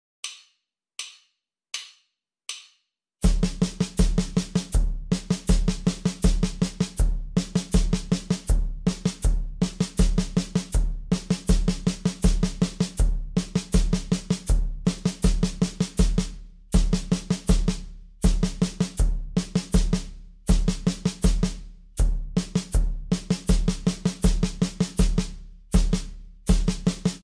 Snare drum rhythm workout featuring sound effects free download
Snare drum rhythm workout featuring broken 16th notes.